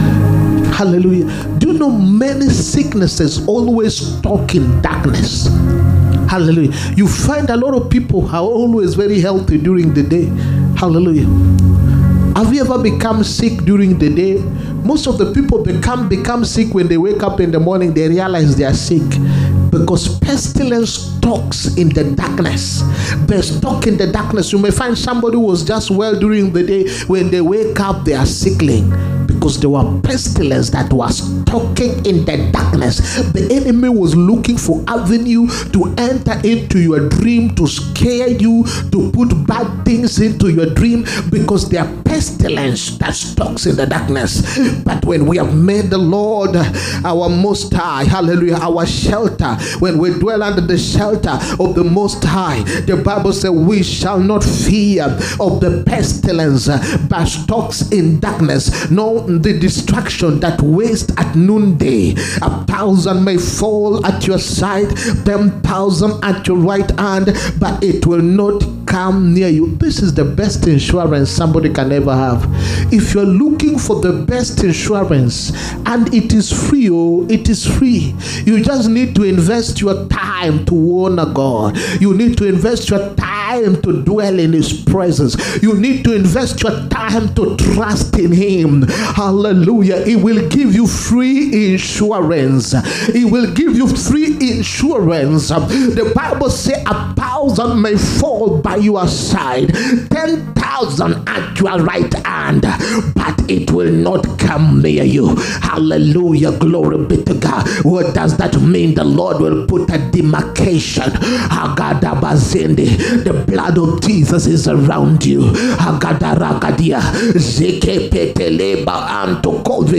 HEALING, PROPHETIC AND DELIVERANCE SERVICE. 15TH MARCH 2025. PART 2.